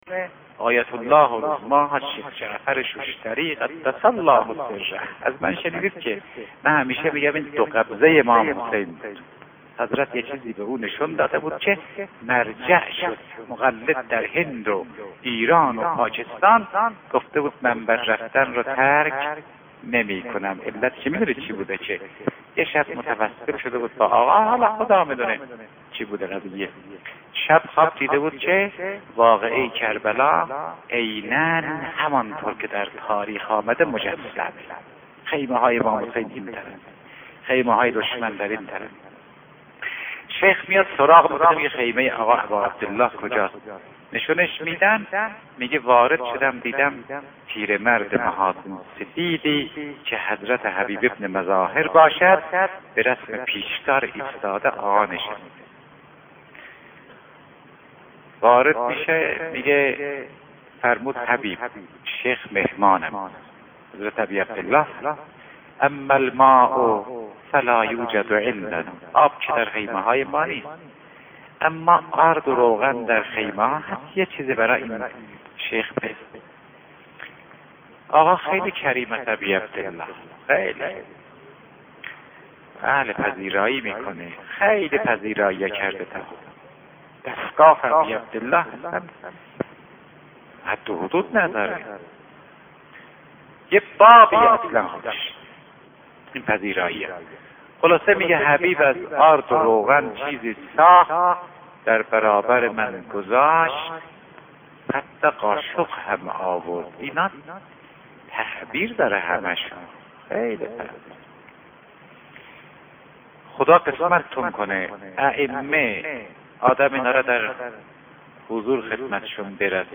گزیده ای از سخنرانی قدیمی استاد فاطمی نیا: